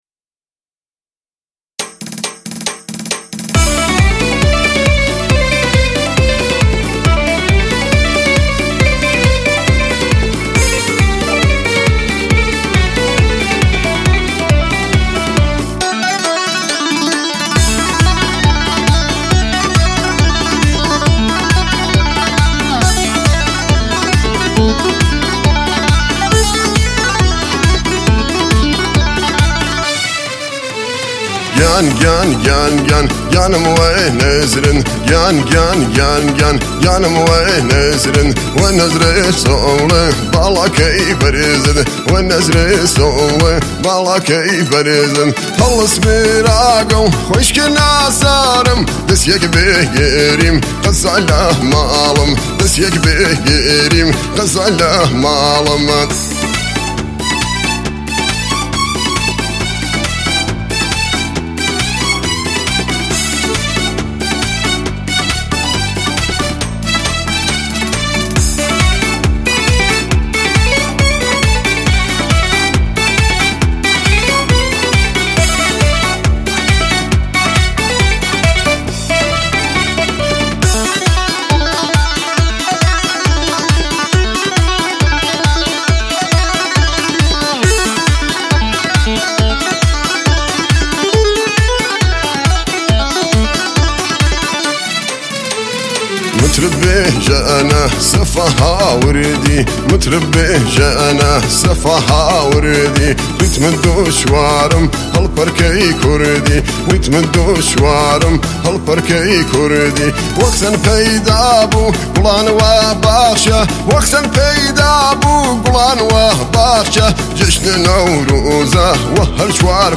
ژانر: پاپ
آهنگ کردی شـاد